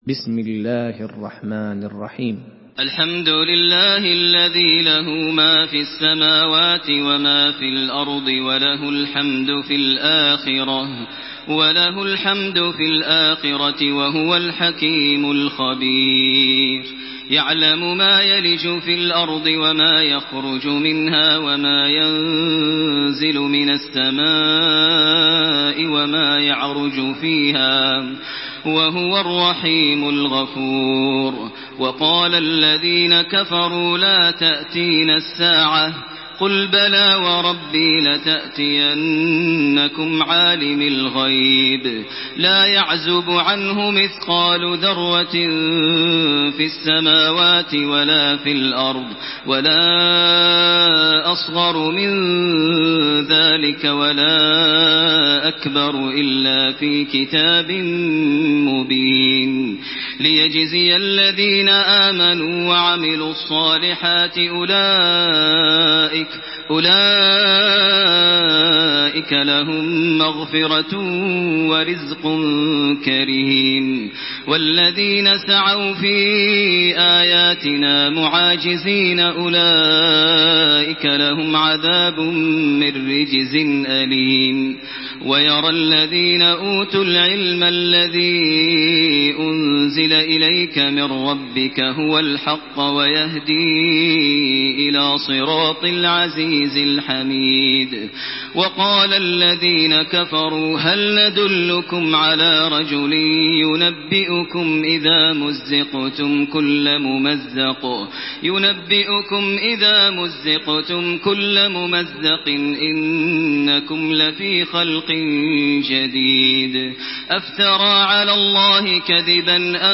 سورة سبأ MP3 بصوت تراويح الحرم المكي 1428 برواية حفص
مرتل